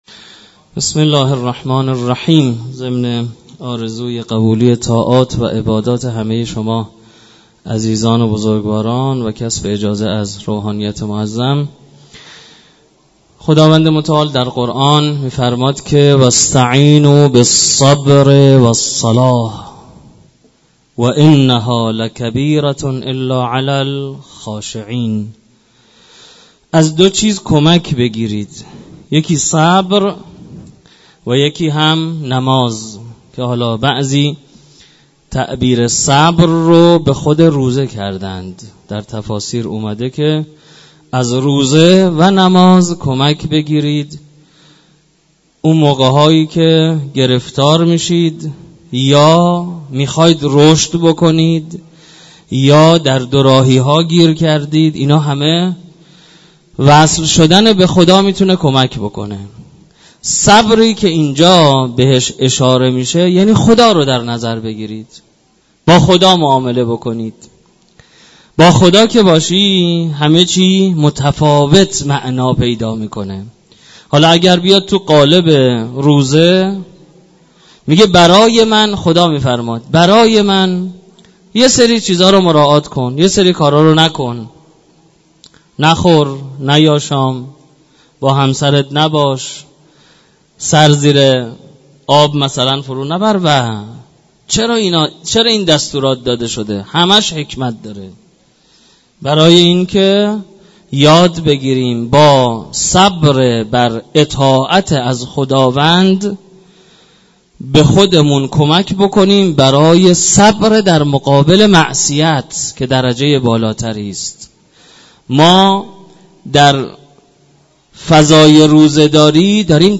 سخنرانی مسئول نهاد رهبری در تفسیر آیه ۴۵ سوره مبارکه بقره